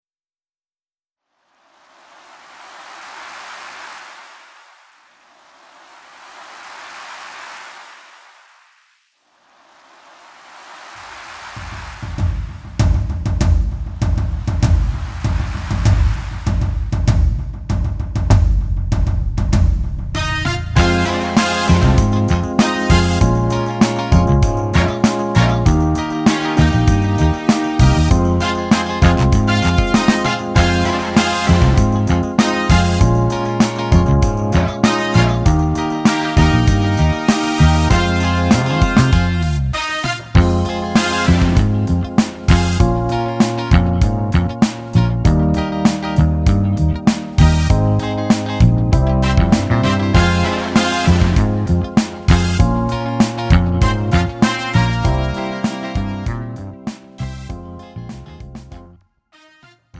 음정 원키
장르 가요 구분 Pro MR